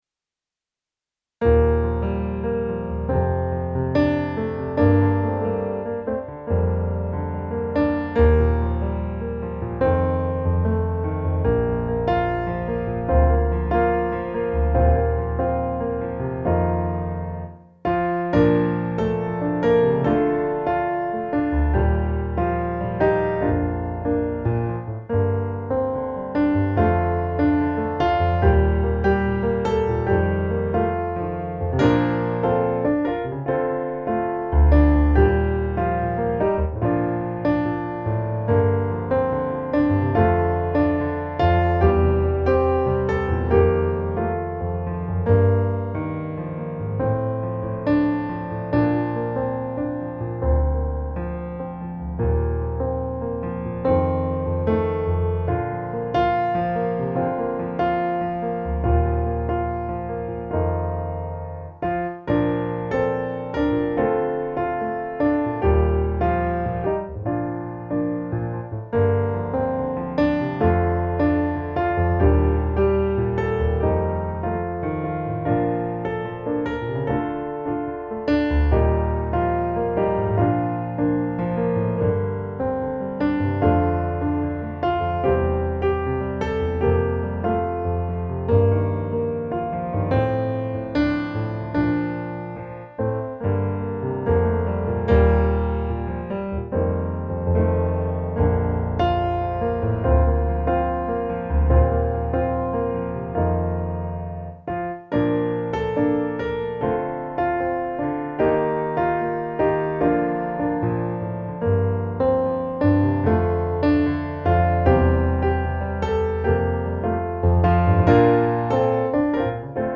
musikbakgrund
Gemensam sång
Musikbakgrund Psalm